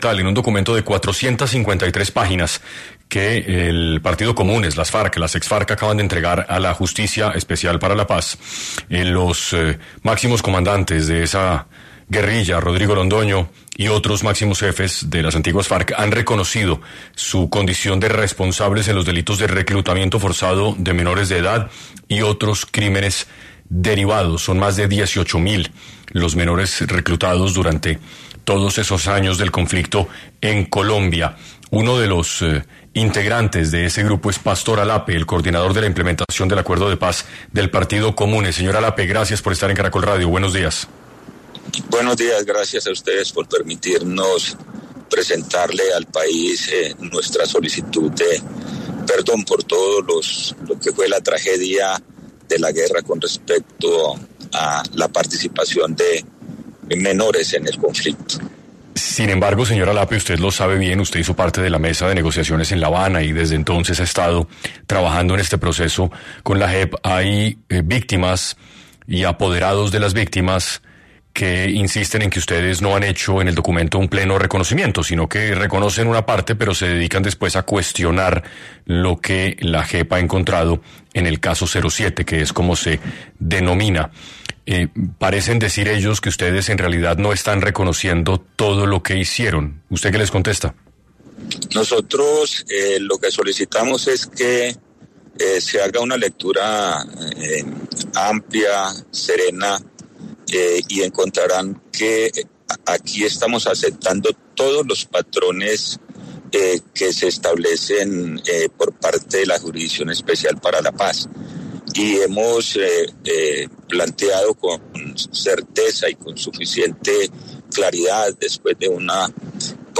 Pastor Alape, coordinador de la implementación del Acuerdo de Paz del Partido Comunes habló en 6AM sobre qué viene tras el reconocimiento del reclutamiento de más de 18.000 menores